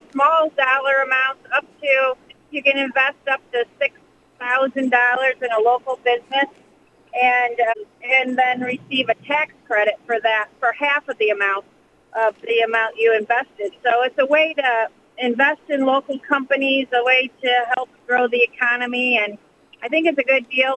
Jenkins-Arno explains how the plan would work: